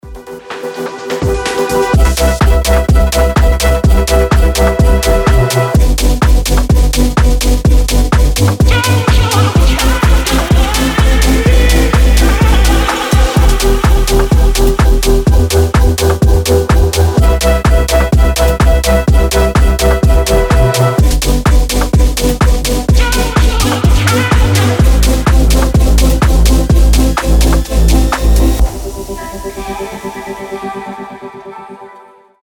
• Качество: 320, Stereo
громкие
мелодичные
EDM
future house
Bass House
электронные